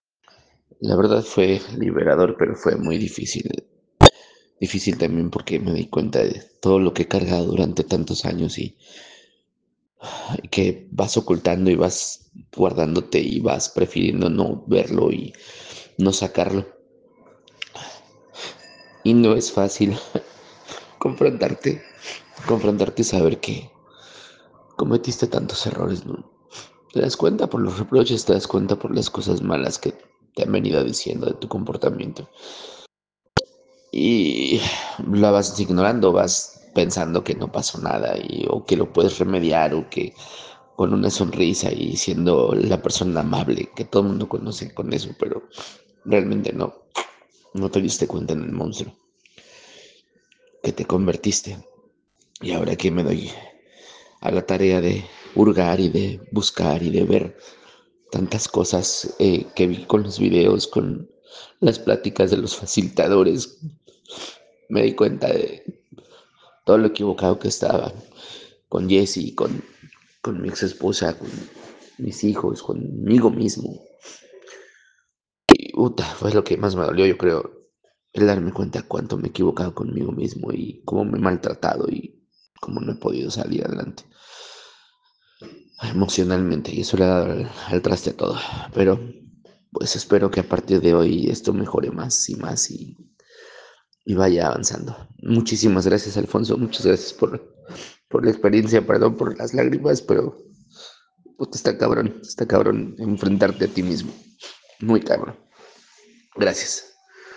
Testimonios en audio:
Testimonio de egresado